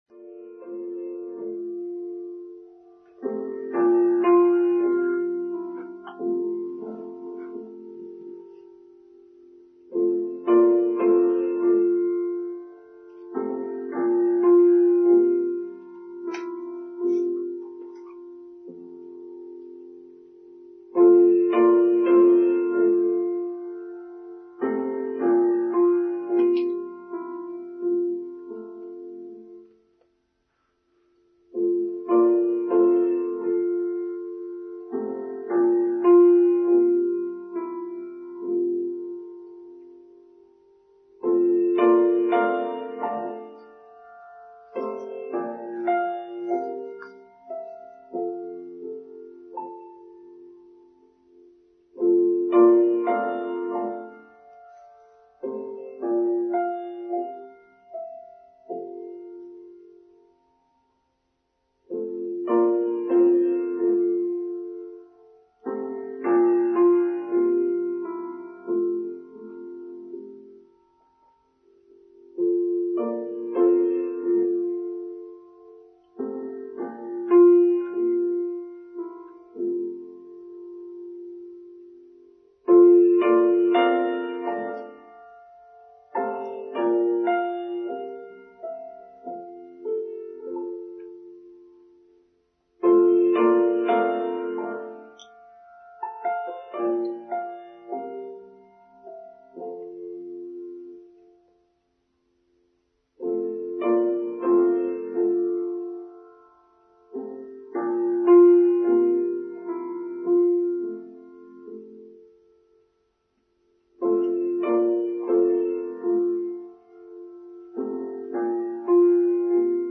Real Change: Online Service for Sunday 24th July 2022